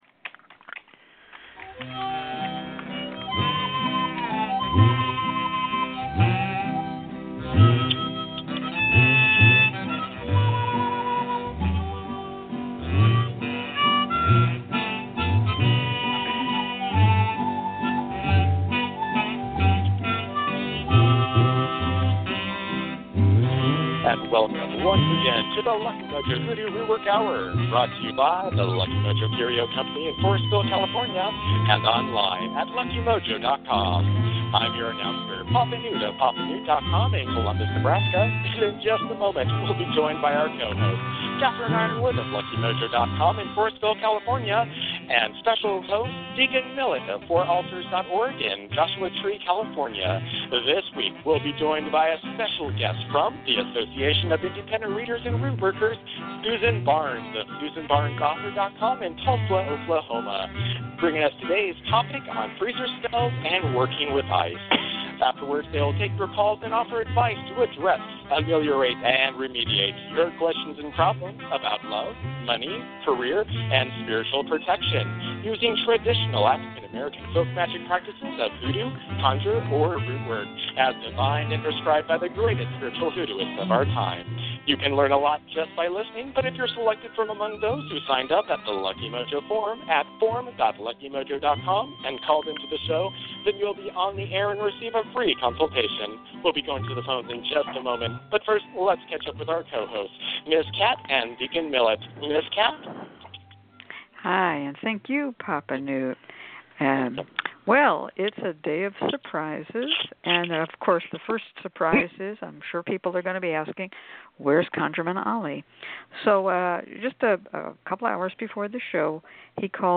present a tutorial on Ice-Cold Magic Spells and provide free readings, free spells, and conjure consultations, giving listeners an education in African-American folk magic.